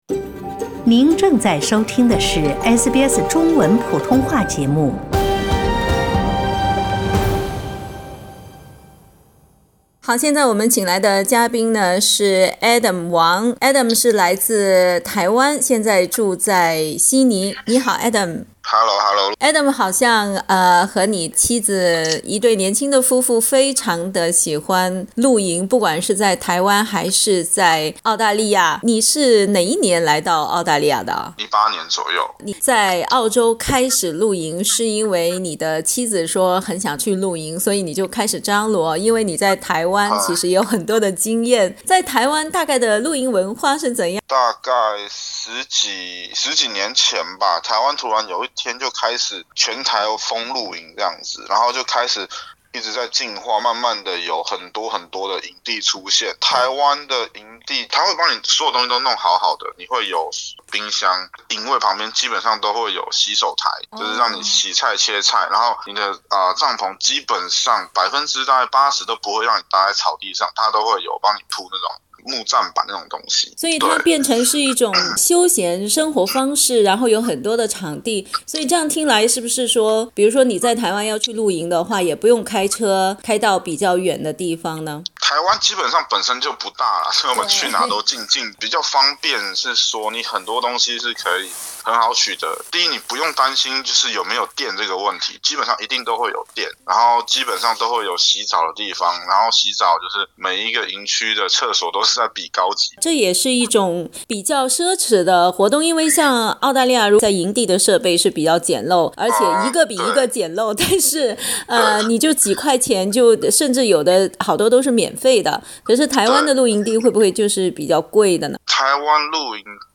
(请听采访） 澳大利亚人必须与他人保持至少 1.5 米的社交距离，请查看您所在州或领地的最新社交限制措施。